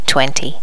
Normalize all wav files to the same volume level.